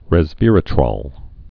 (rĕz-vîrĭ-trôl, -trŏl, -trōl)